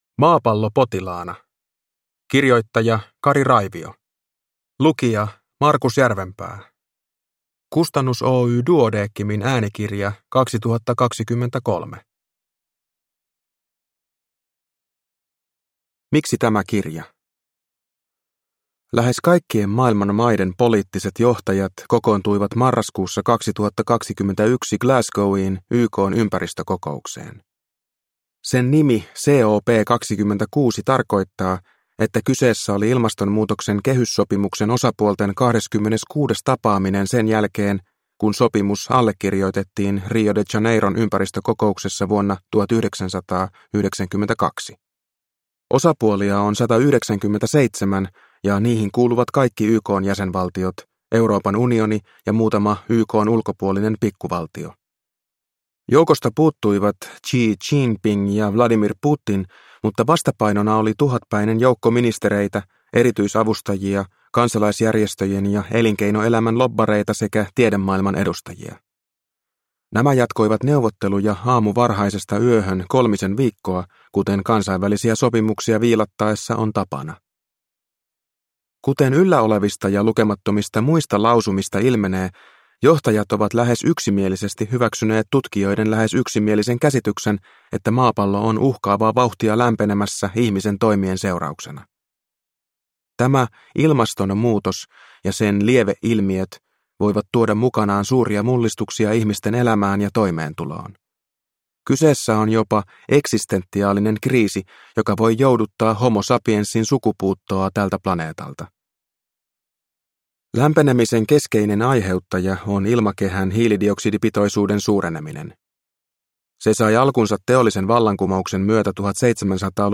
Maapallo potilaana – Ljudbok